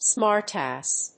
/ˈsmɑˌrtæs(米国英語), ˈsmɑ:ˌrtæs(英国英語)/
アクセントsmárt àss [àrse]